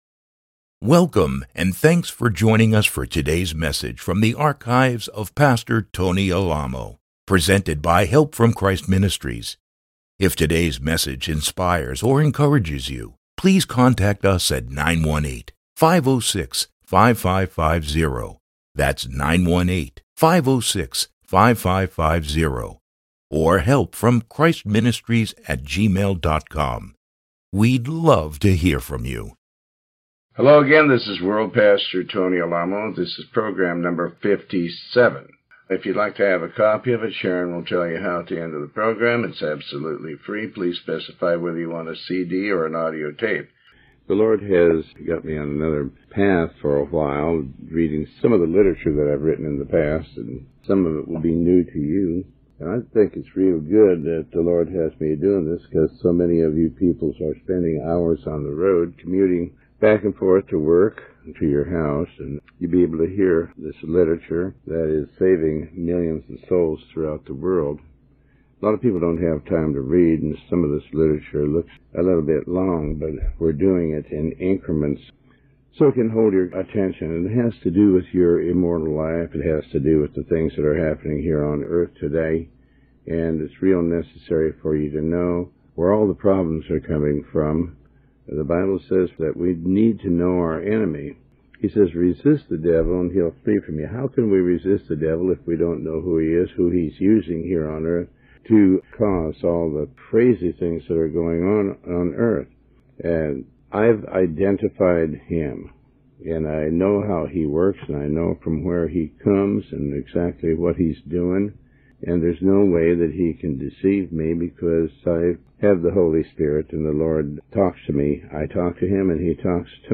Sermon 57A